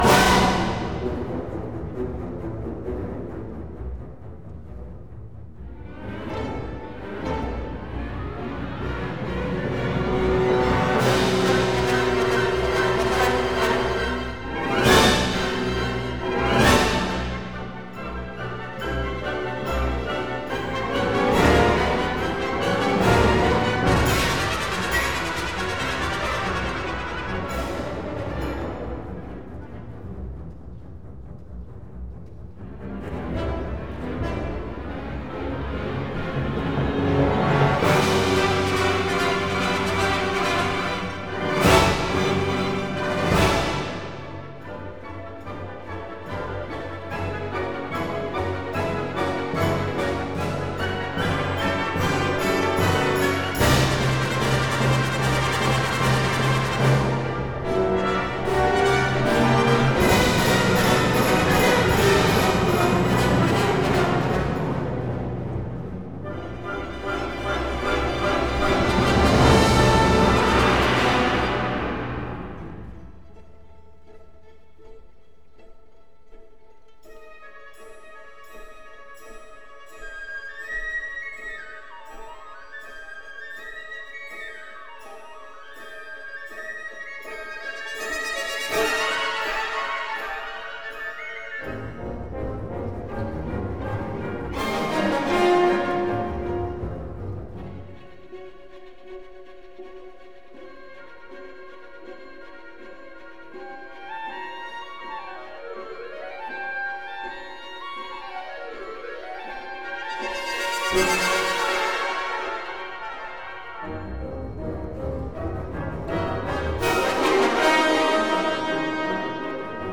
by Montreal Symphony Orchestra and Chorus; Charles Dutoit | Ravel: Daphnis et Chloé